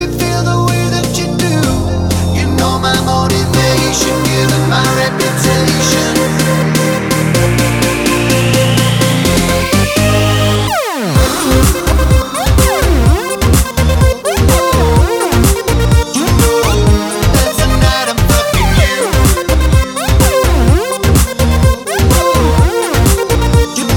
With Rapper Pop